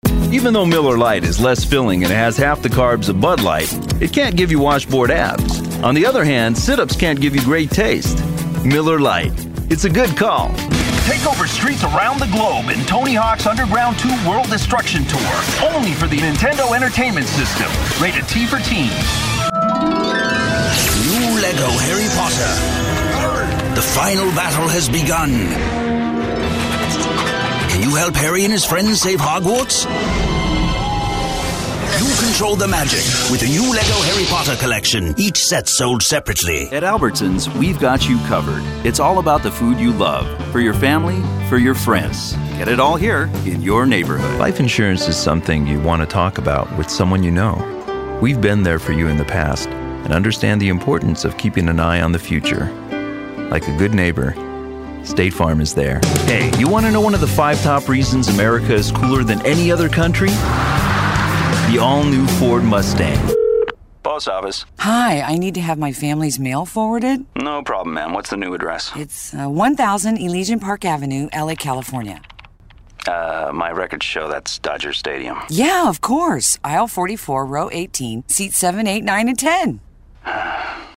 britisch